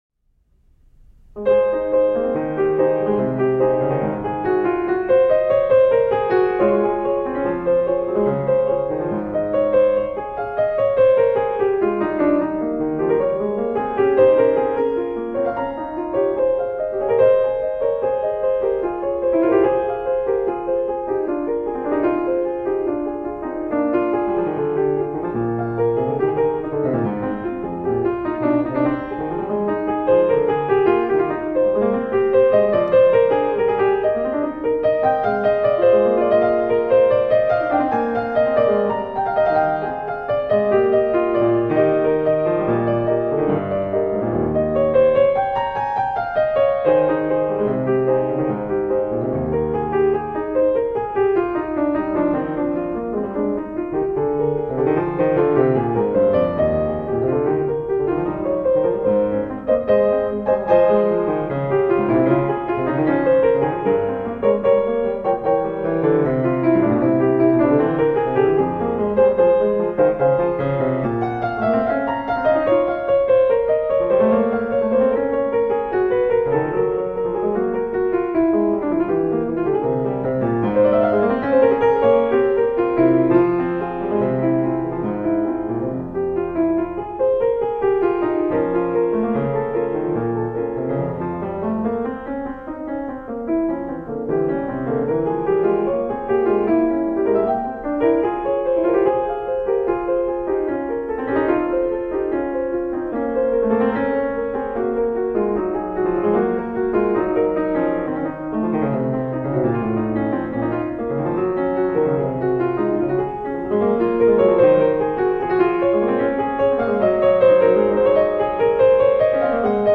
solo piano music
Classical, Baroque, Instrumental Classical, Classical Piano